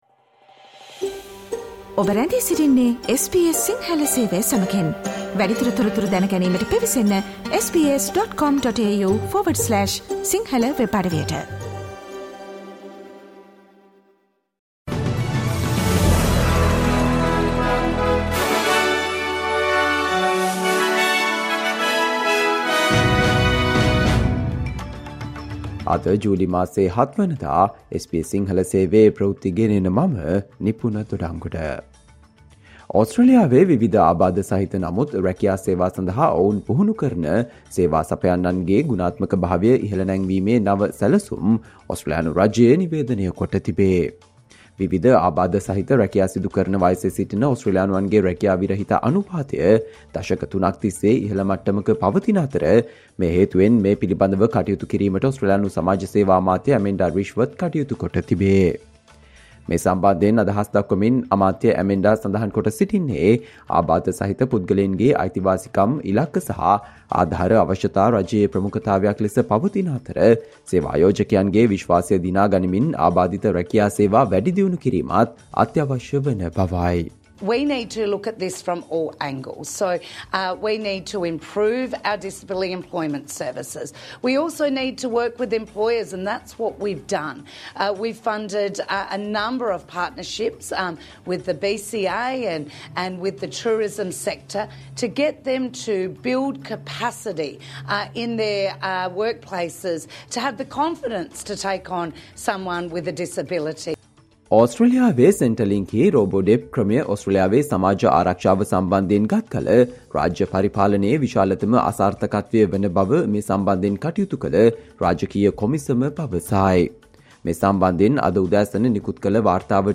Australia news in Sinhala, foreign and sports news in brief - listen, today - Friday 07 July 2023 SBS Radio News